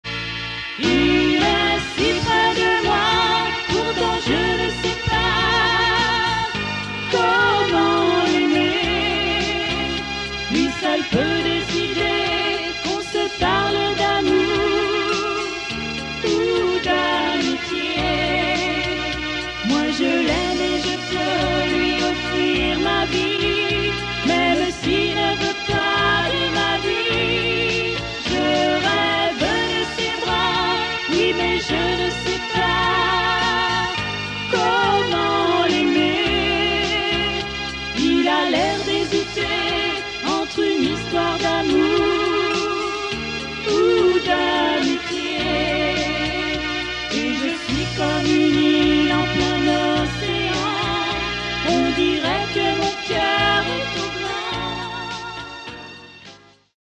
EXTRAIT SLOWS